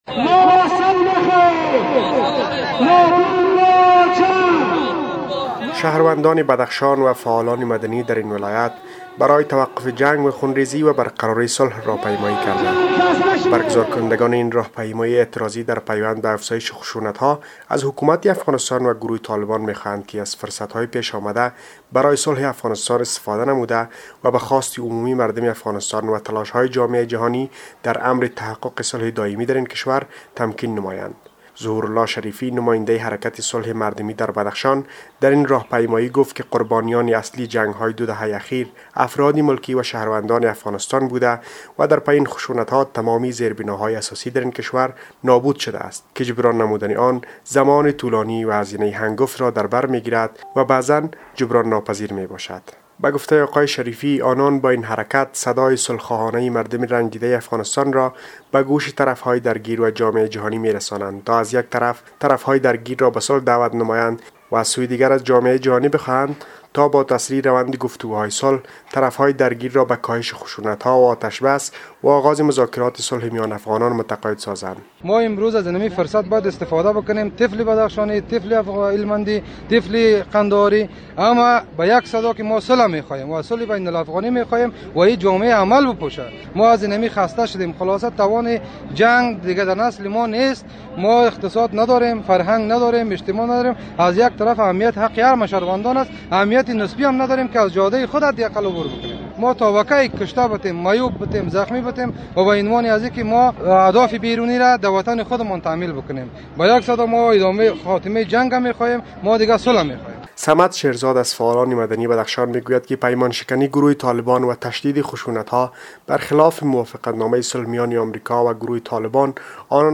به گزارش خبرنگار رادیو دری، باشندگان بدخشان در این راه پیمایی ازطرف‌های درگیر می‌خواهند که به خشونت‌های خونین پایان دهند.